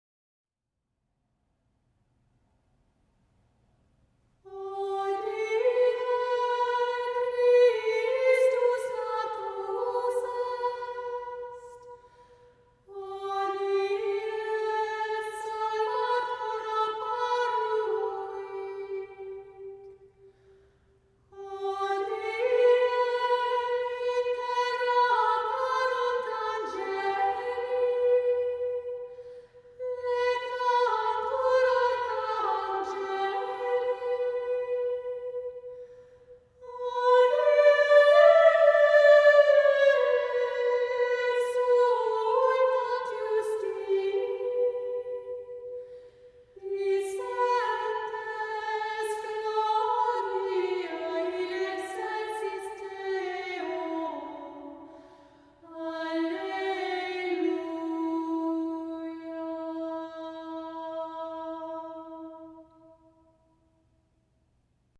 We’ve already heard some of the earliest music of the Catholic Church, a genre of music called “Gregorian chant.”
We already possess the vocabulary to describe some of Gregorian chant’s characteristic features: monophonic texture, non-metrical rhythms, and cadences at the end of every phrase.